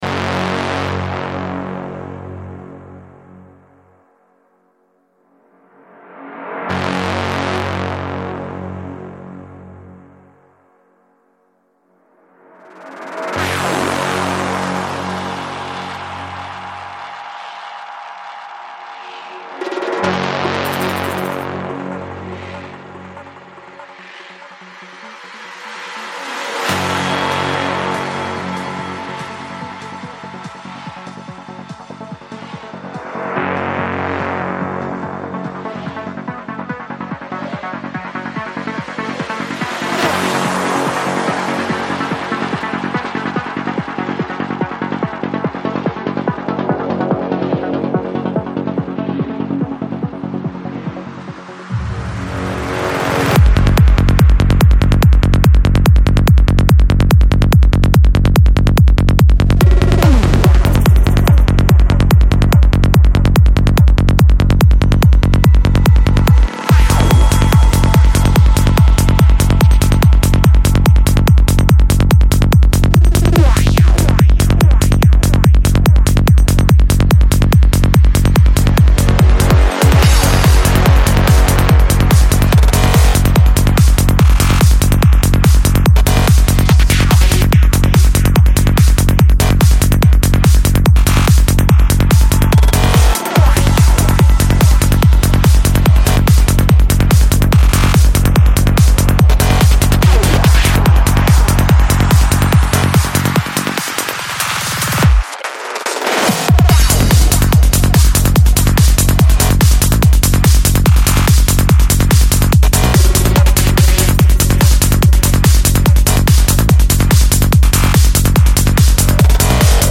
Жанр: Electro
20:32 Альбом: Psy-Trance Скачать 10.34 Мб 0 0 0